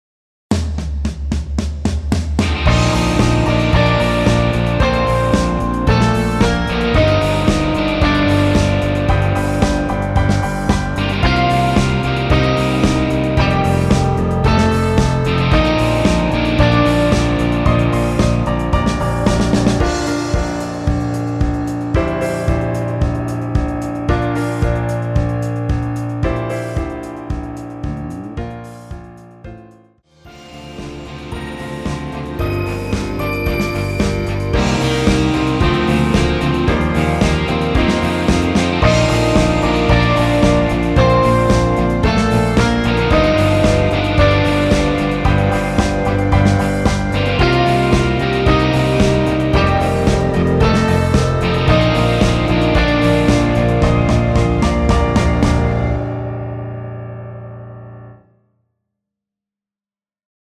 Bb
◈ 곡명 옆 (-1)은 반음 내림, (+1)은 반음 올림 입니다.
앞부분30초, 뒷부분30초씩 편집해서 올려 드리고 있습니다.